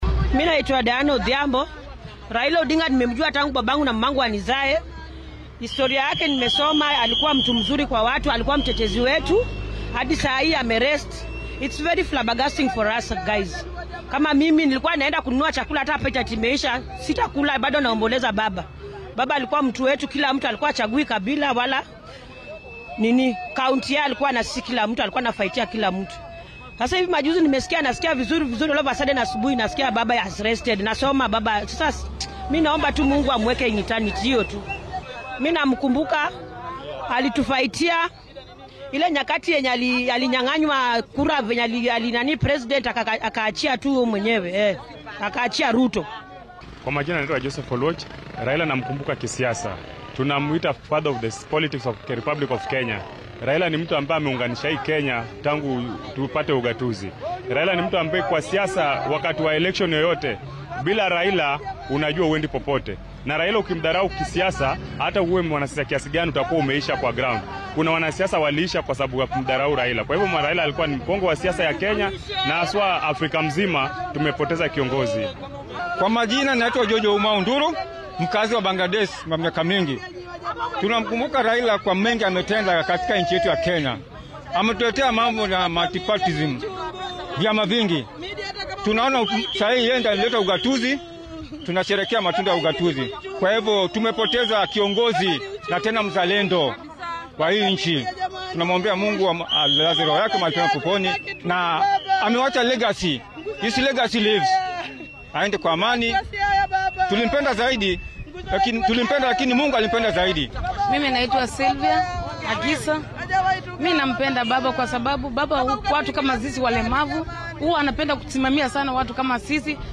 Shacabka deggan gobollada kala duwan ee dalka ayaa si weyn uga falceliyay geerida ku timid Raa’iisul wasaarihii hore ee dalka Raila Odinga. Waxaa ay badankooda eedeyeen dadka ka agdhawaa hoggaamiyan oo ay sheegeen inay qariyeen xanuunkiisa illa uu ka geeriyooday. Qaar ka mid ah bulshada oo dareenkooda la wadaagay warbaahinta ayaa waxaa hadaladooda ka mid ahaa.